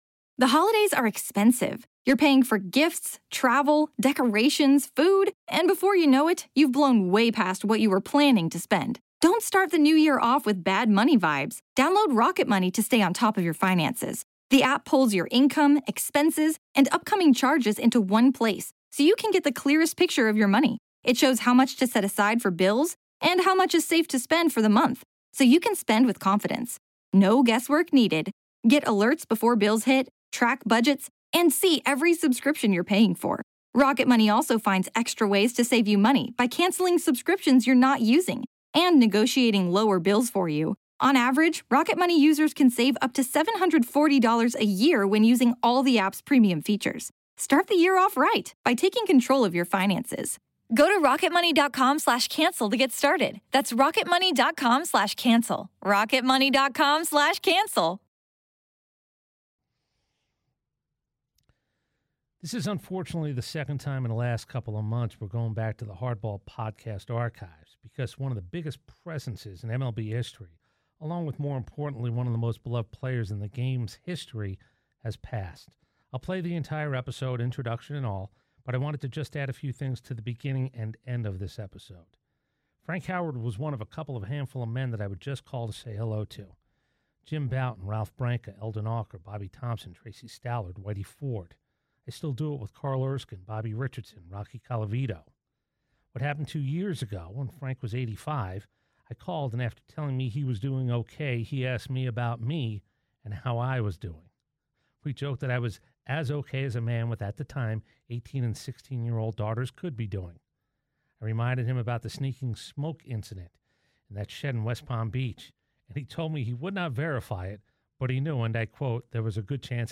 I hope you enjoy this look back to the last sit down that Frank Howard did..,2 yrs ago and as sharp as it gets.